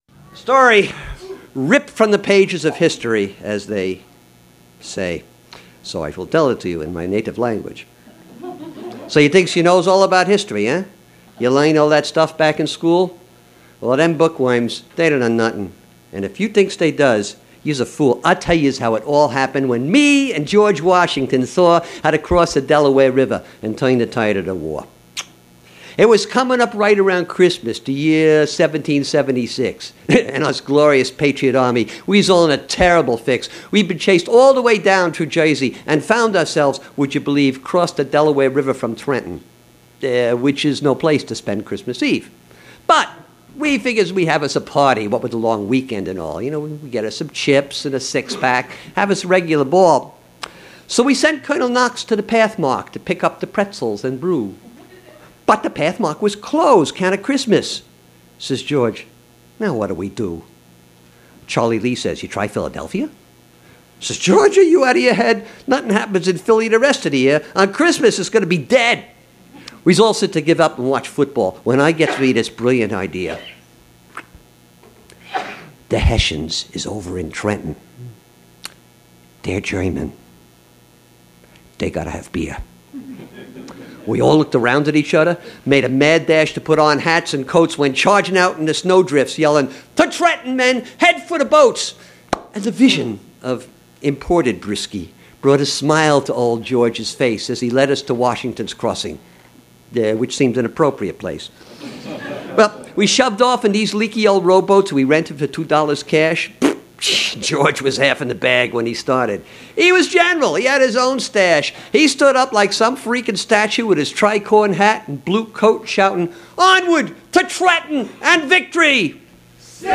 But he can also deliver more light-hearted fare, such as The Battle of Trenton, a bit of faux history done in authentic dialect.